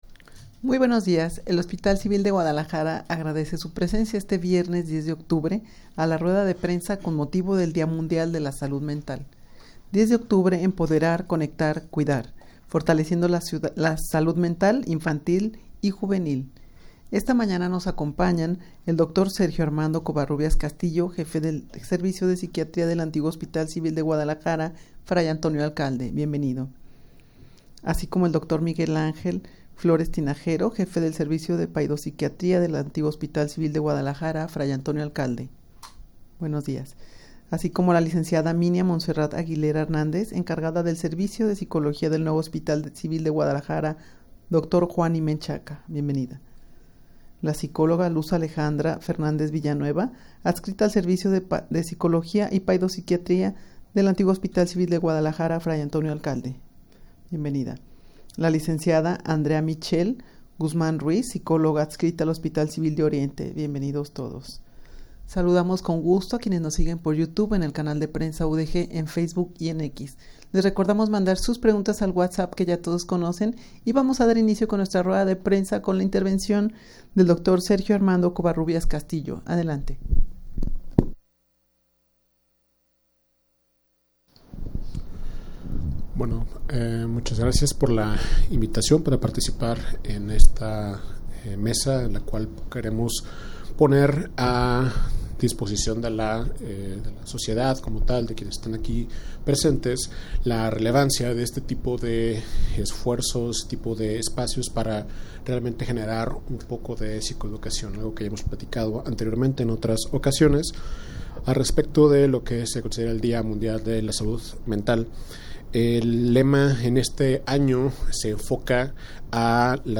Audio de la Rueda de Prensa
rueda-de-prensa-con-motivo-del-dia-mundial-de-la-salud-mental_0.mp3